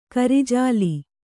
♪ kari jāli